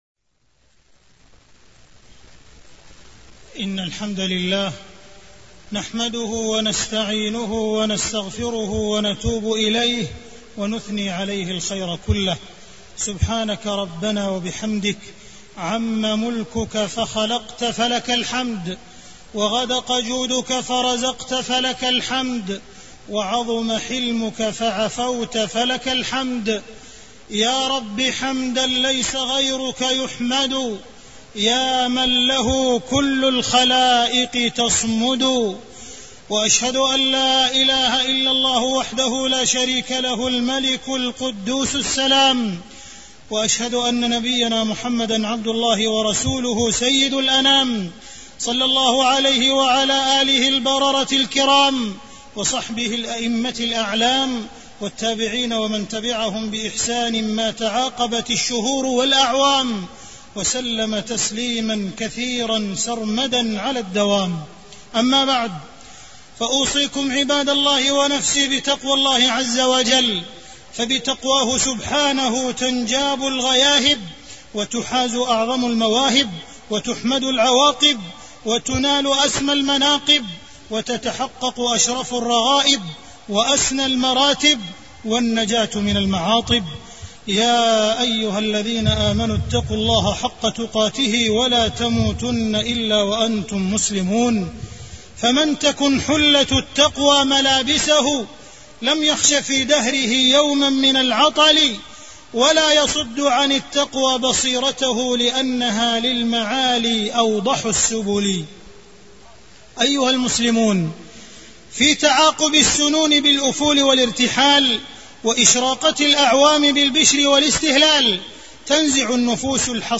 تاريخ النشر ٢٣ محرم ١٤٢٩ هـ المكان: المسجد الحرام الشيخ: معالي الشيخ أ.د. عبدالرحمن بن عبدالعزيز السديس معالي الشيخ أ.د. عبدالرحمن بن عبدالعزيز السديس غزة والعزة